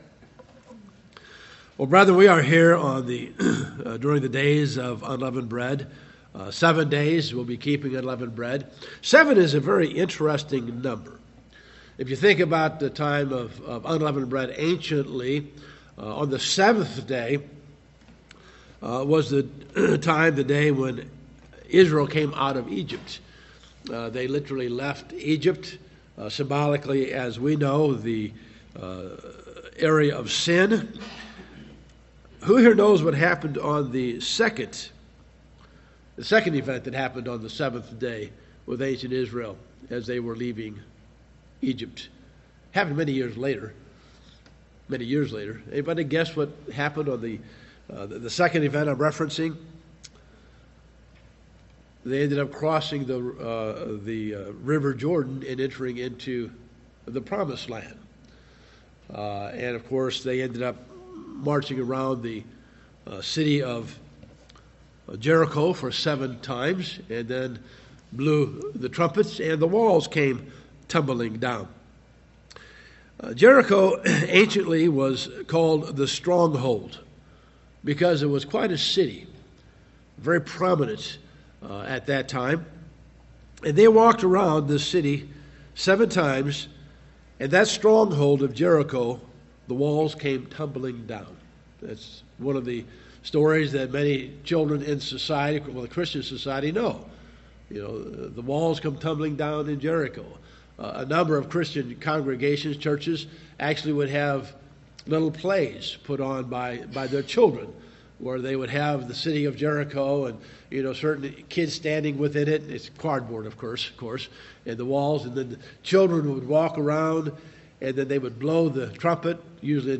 Given in Lawton, OK